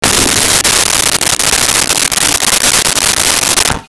Toilet Flush
Toilet Flush.wav